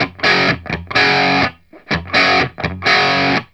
RIFF1-125FS.wav